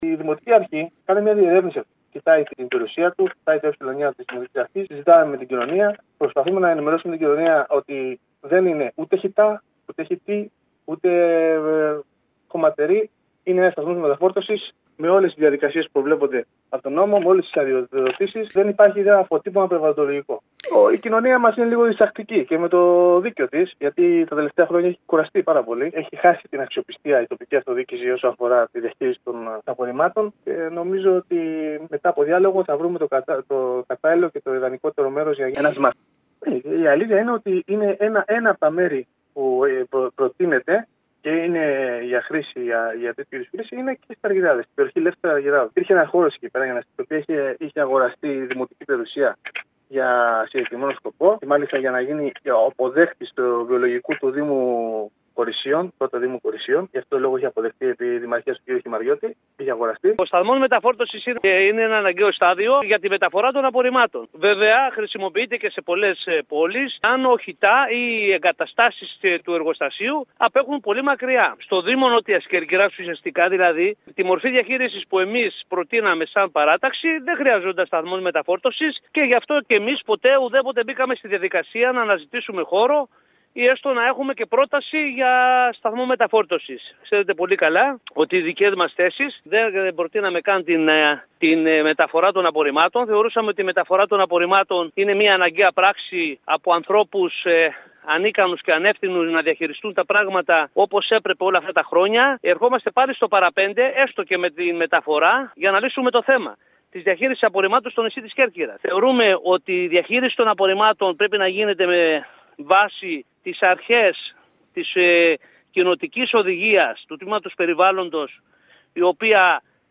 Δύο σοβαρά θέματα για τη Νότια Κέρκυρα συζήτησε το Δημοτικό συμβούλιο της περιοχής χθες και προχθές, σε δύο συνεδριάσεις με τηλεδιάσκεψη.
Στο ηχητικό απόσπασμα που ακολουθεί, ακούμε τον αντιδήμαρχο Τεχνικών υπηρεσιών της Νότιας Κέρκυρας, Νίκο Κουρτέση, και τους επικεφαλής παρατάξεων Γιάννη Πανδή και Γιώργο Κουλούρη.